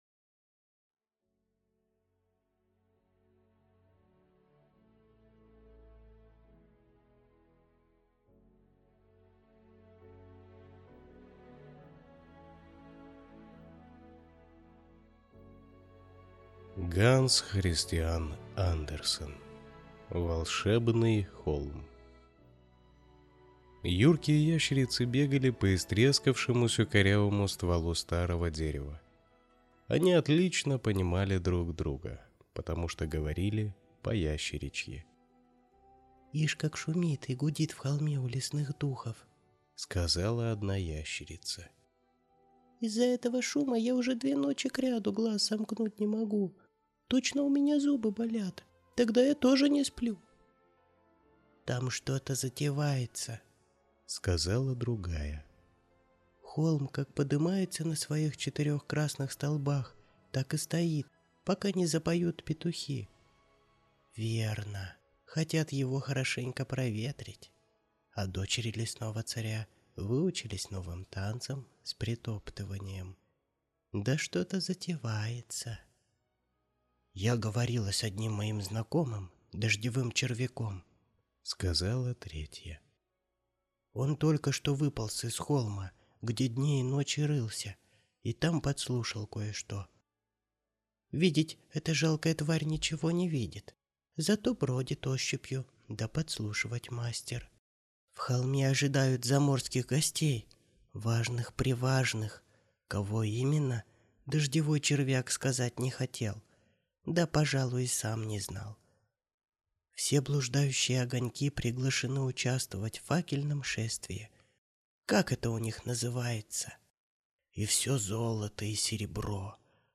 Аудиокнига Волшебный холм | Библиотека аудиокниг